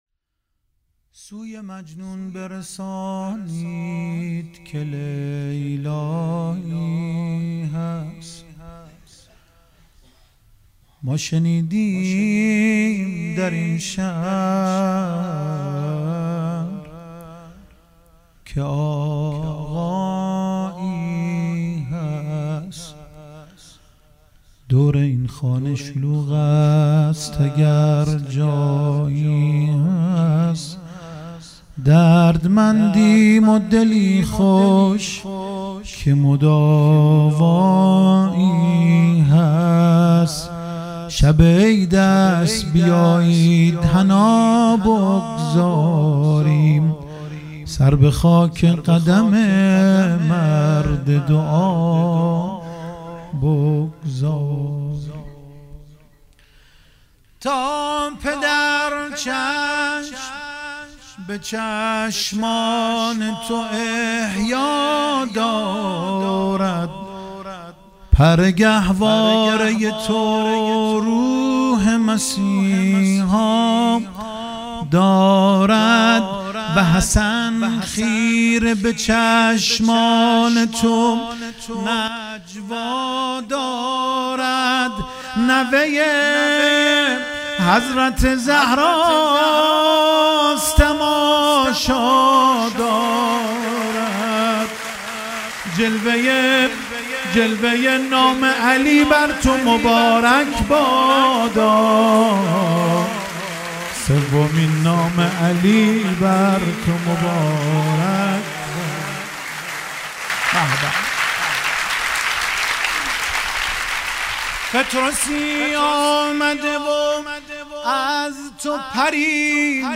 4 اردیبهشت 97 - هیئت ریحانه النبی - مدح - سوی مجنون برسانید که لیلایی هست